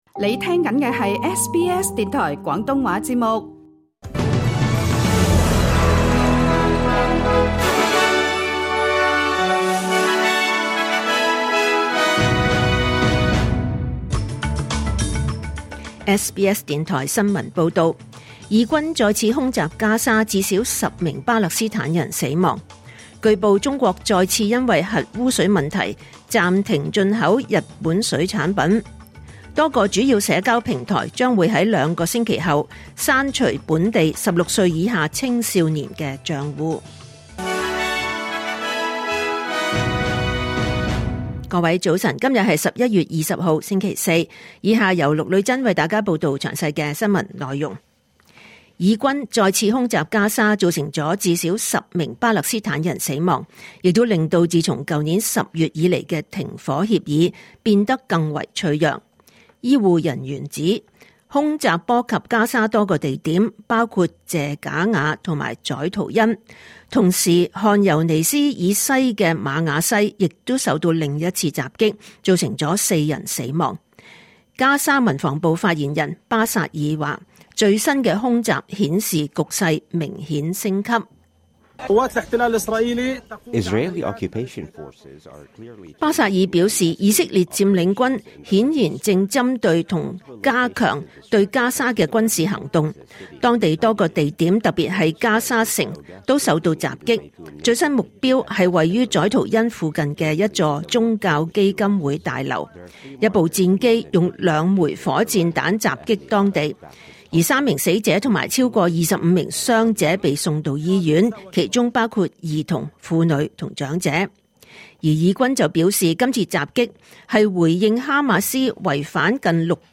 2025年11月20日SBS廣東話節目九點半新聞報道。